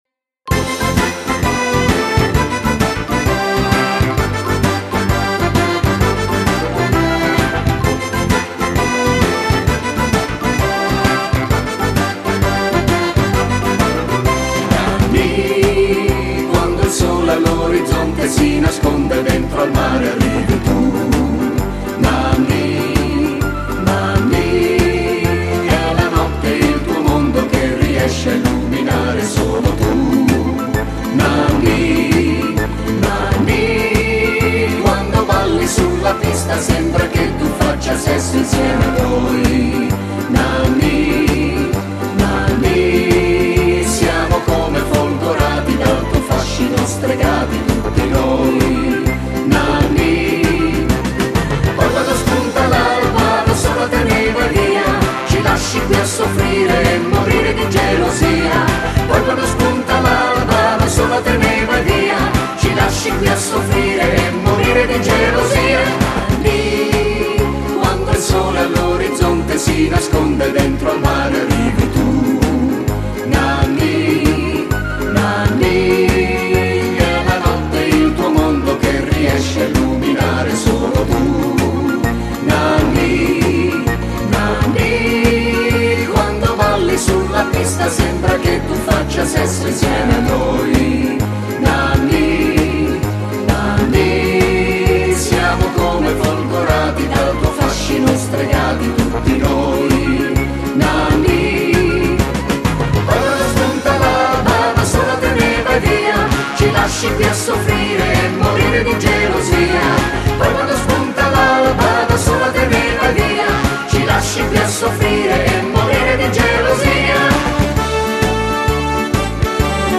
Genere: Tarantella